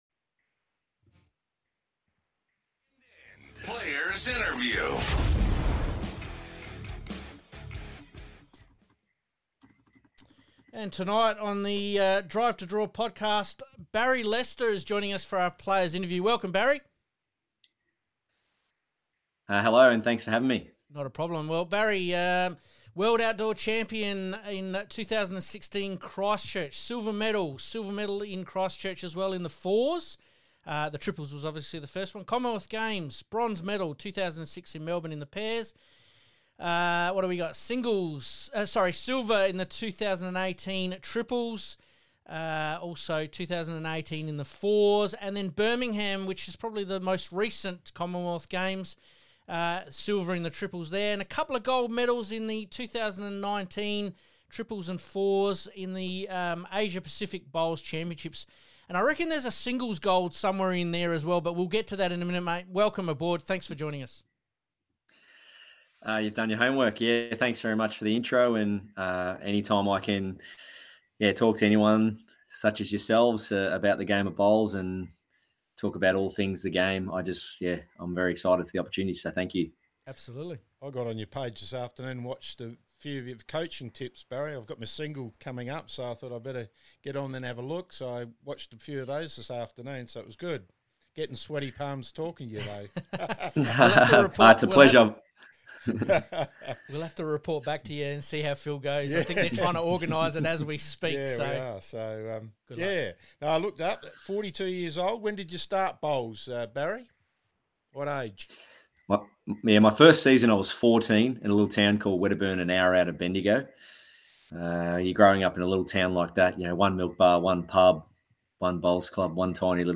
PLAYER INTERVIEW - Interview (only) with this weeks special guest